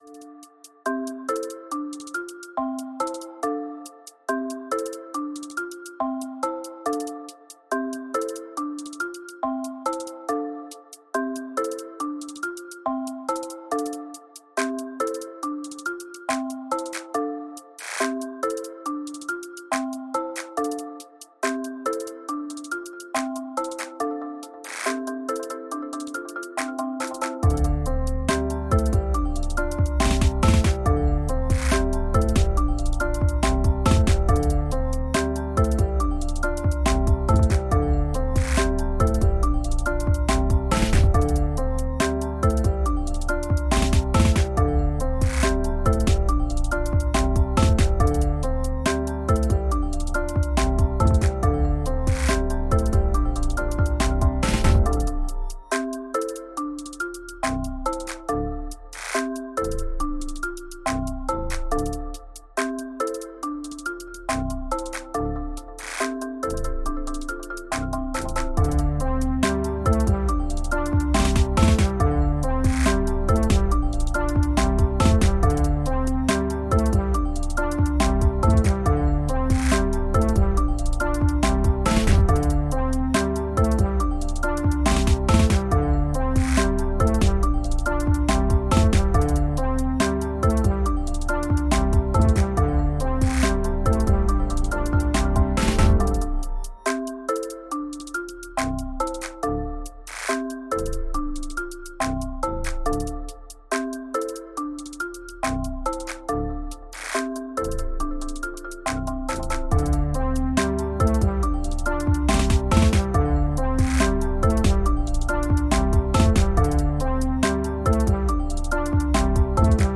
Musique Rap, trap, boombap libre de droit pour vos projets.